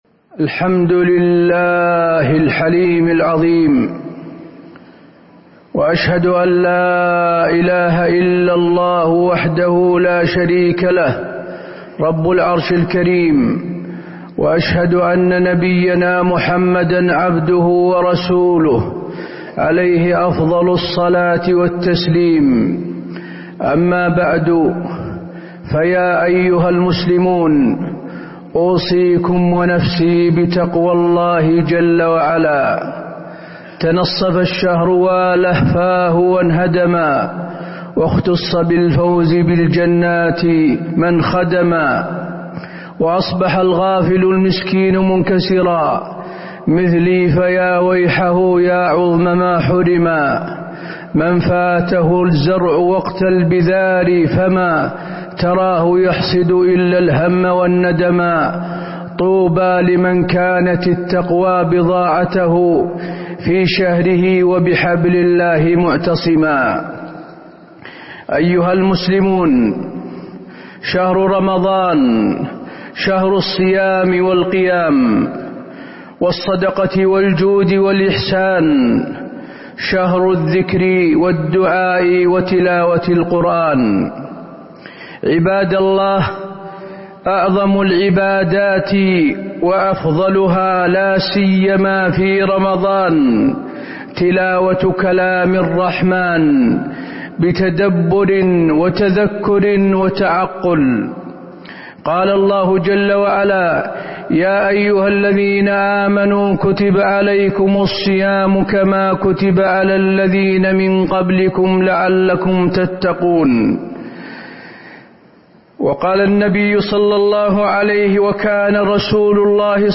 تاريخ النشر ١٤ رمضان ١٤٤٣ هـ المكان: المسجد النبوي الشيخ: فضيلة الشيخ د. حسين بن عبدالعزيز آل الشيخ فضيلة الشيخ د. حسين بن عبدالعزيز آل الشيخ مقاصد القرآن الكريم